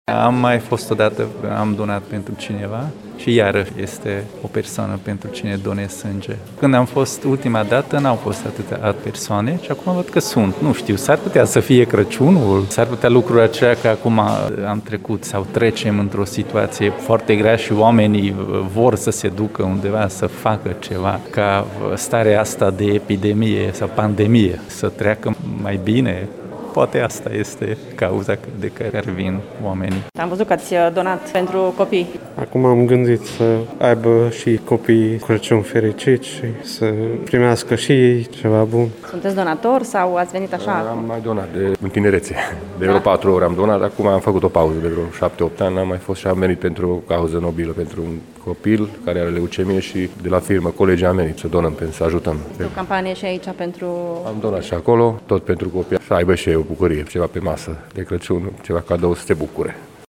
Mulți dintre târgumureșenii care au venit marți să doneze sânge spun că nu au mai donat de ani de zile.